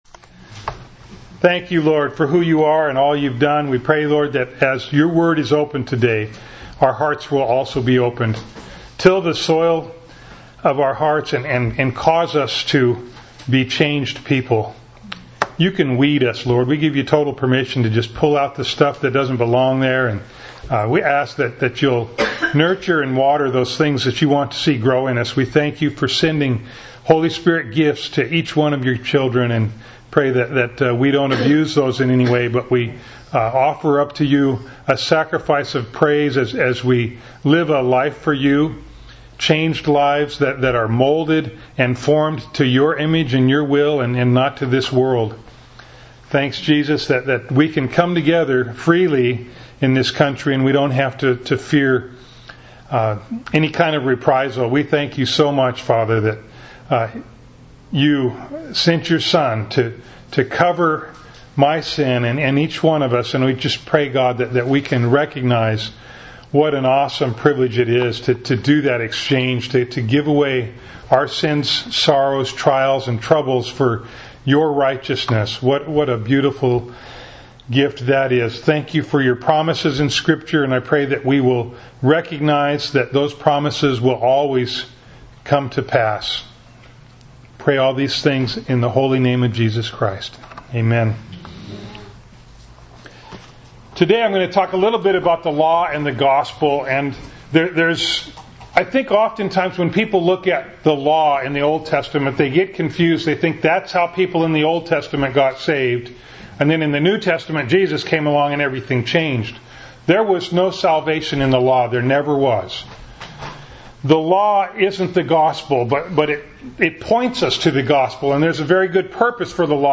Bible Text: Galatians 3:15-22 | Preacher